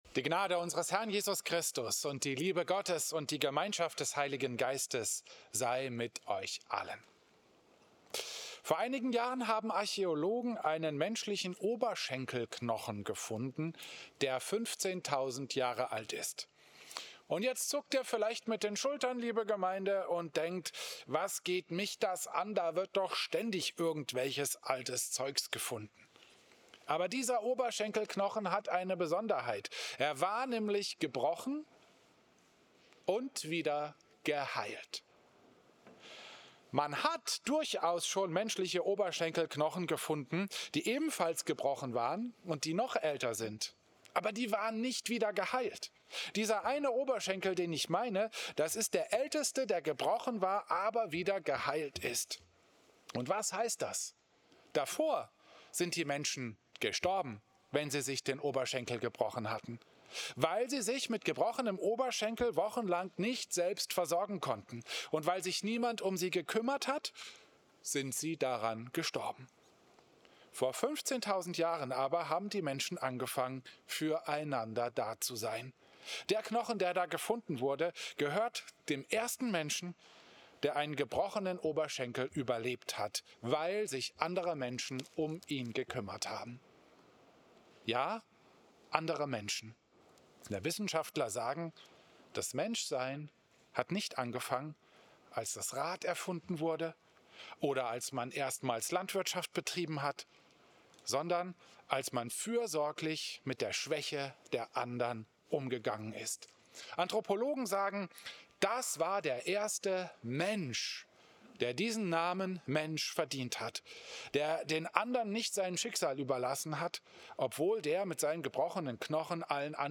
Predigt
Christus-Pavillon Volkenroda, 26.